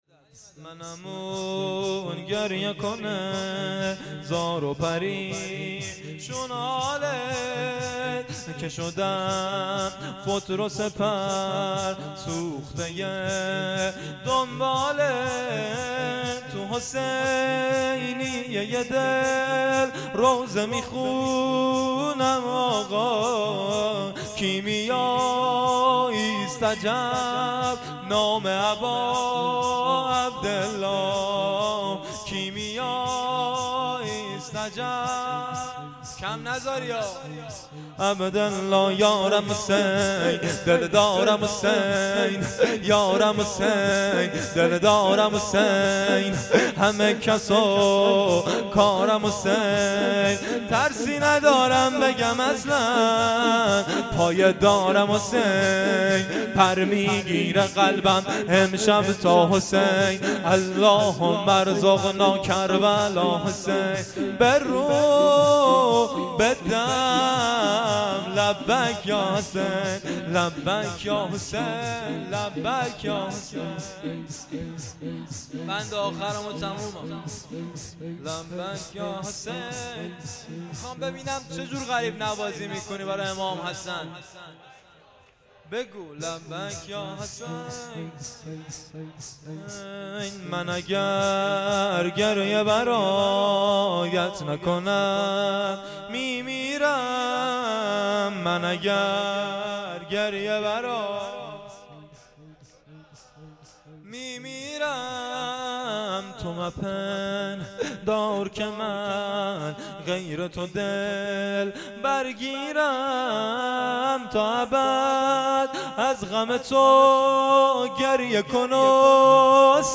شور
مراسم عزاداری دهه اول محرم الحرام 1399 - مسجد صاحب الزمان (عج) هرمزآباد
شب ششم - دهه اول محرم 99